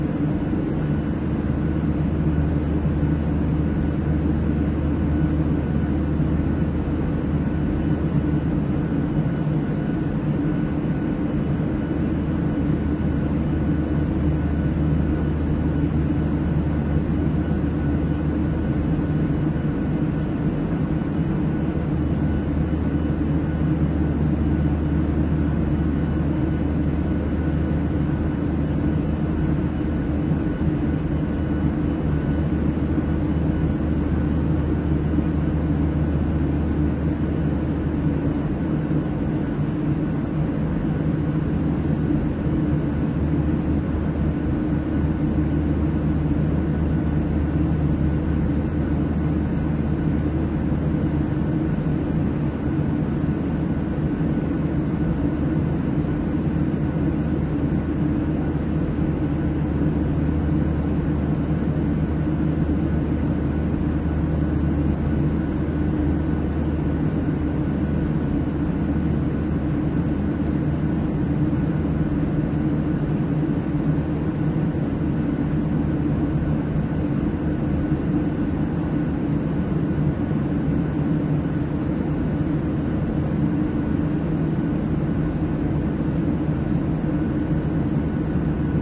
航空旅行 / 航空公司 / 航空公司 " 机上氛围 波音 737838
描述：1:29的澳洲航空公司所属的波音737（800系列）客机的机内氛围。大约在布里斯班和阿德莱德之间的一半路程。录音链。
Tag: 飞机 飞机 气氛 客舱噪音 现场记录 飞机 飞机 喷气发动机